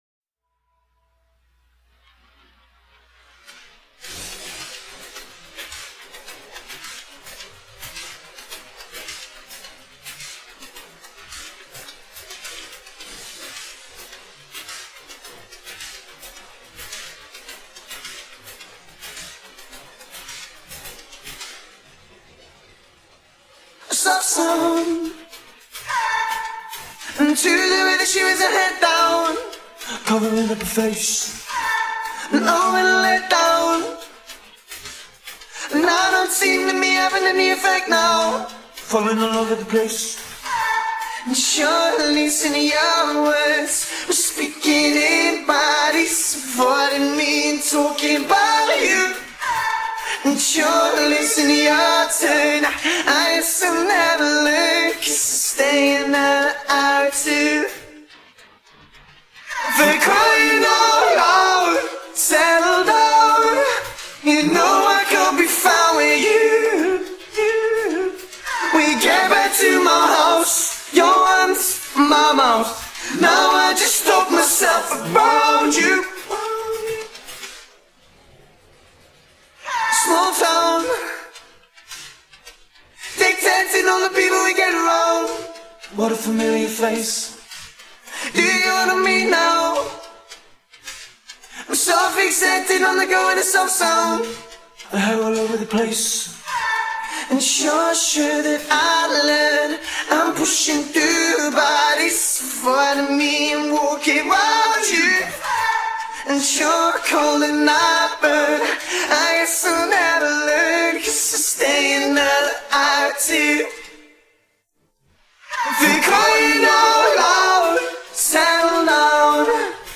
(Vocals Only)